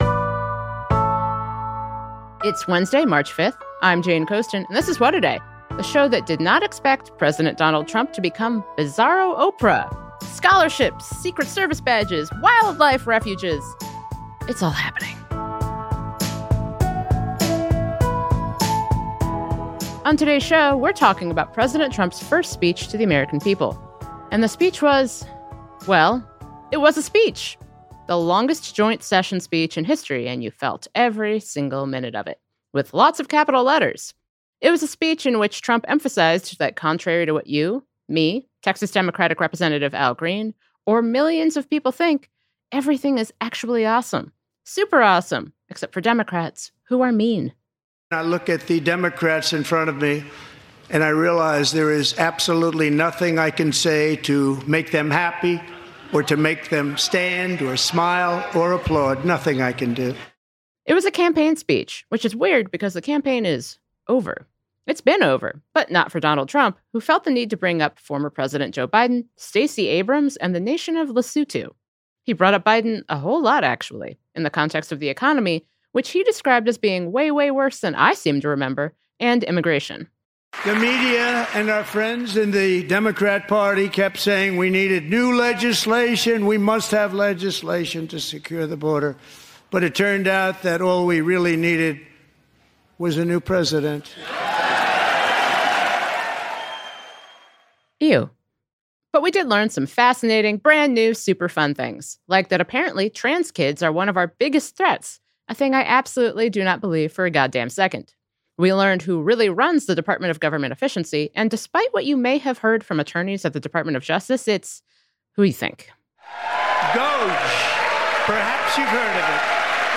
Trump talked about everything from tariffs to immigration to trans kids to the economy. Pod Save America’s Jon Lovett joins us to debrief the president’s rambling speech.